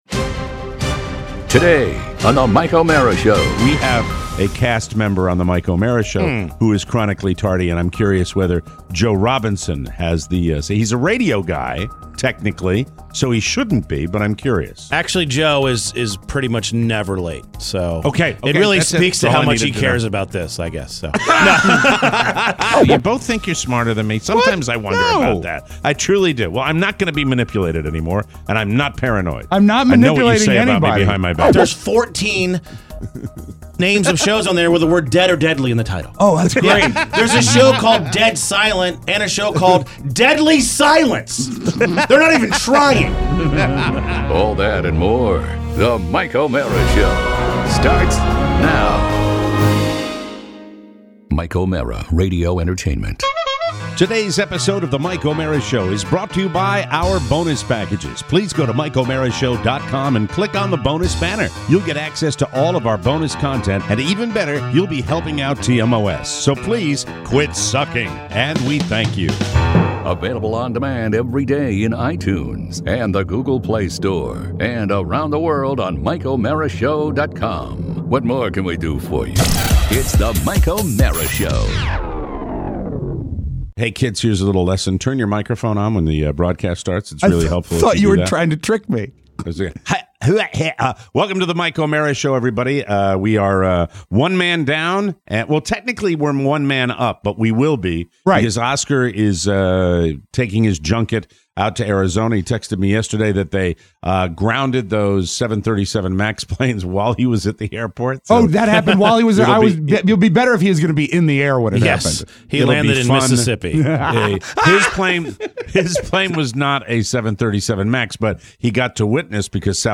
We are joined in studio